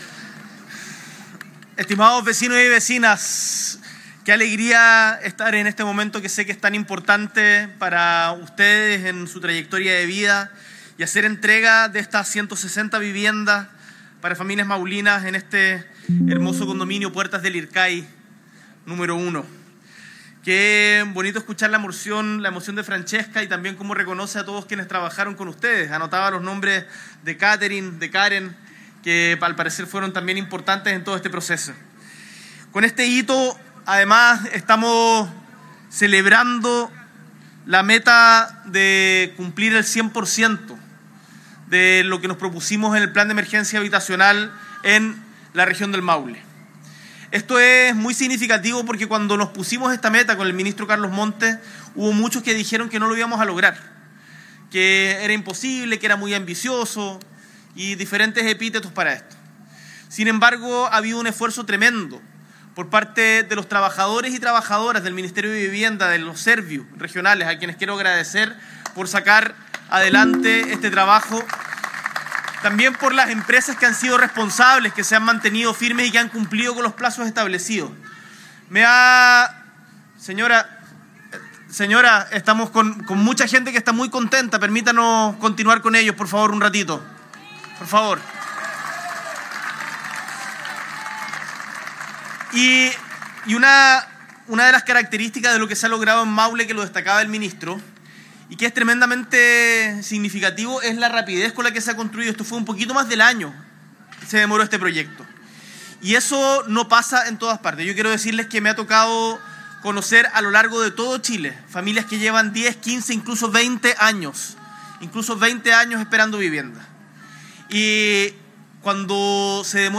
S.E. el Presidente de la República, Gabriel Boric Font, encabeza la entrega del Condominio Puertas de Lircay I, hito que marca el cumplimiento del Plan de Emergencia Habitacional en la Región del Maule.